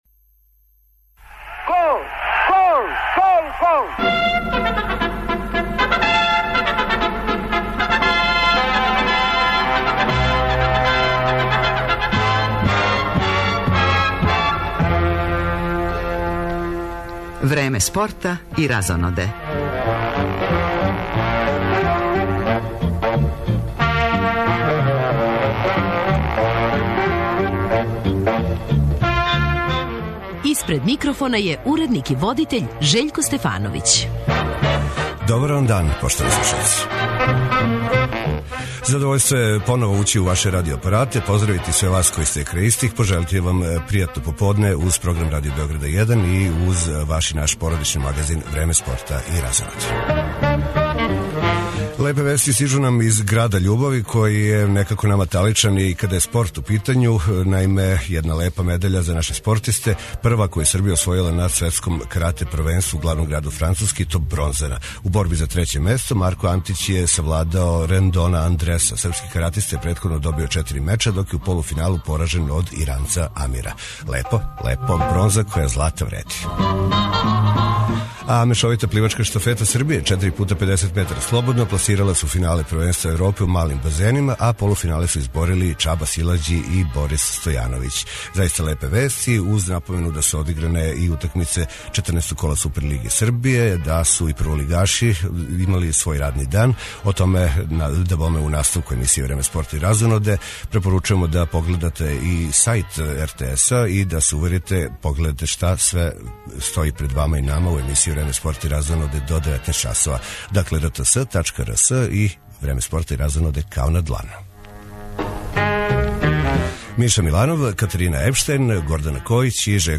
И овај породични магазин почињемо прегледом резултата и извештајима са утакмица Супер лиге Србије. У емисији, током које ће бити речи и о кошарци, формули 1, мачевању и другим дисциплинама, идемо у сусрет Европском првенству у рукомету за даме, чији је домаћин Србија од 4. децембра.